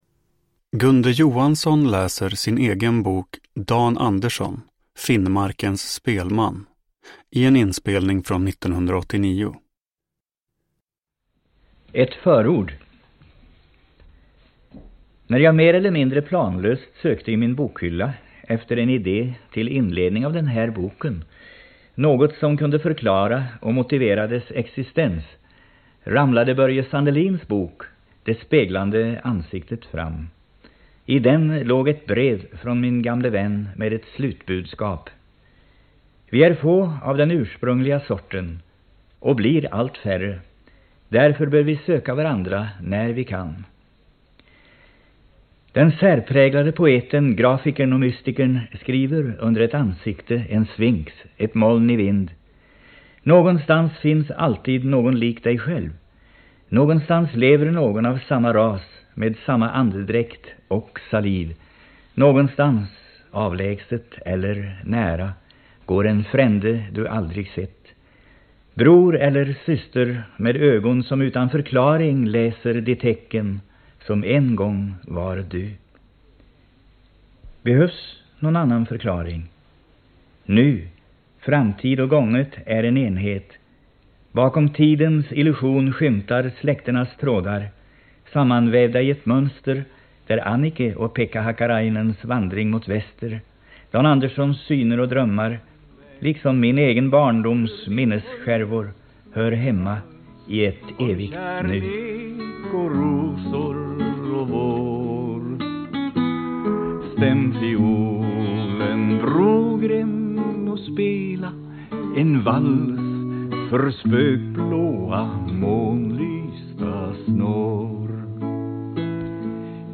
Dan Andersson - Finnmarkens spelman – Ljudbok